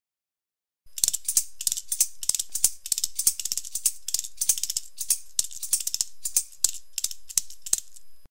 URM Sonos de Sardigna: nuovi strumenti - Fionde sonore o sistri
Fionde.mp3